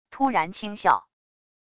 突然轻笑wav音频